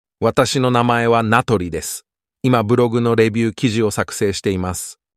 テキスト読み上げ、AI音声作成
なんということでしょう、一瞬で音声が作成されました
出来上がった音声はこんな感じ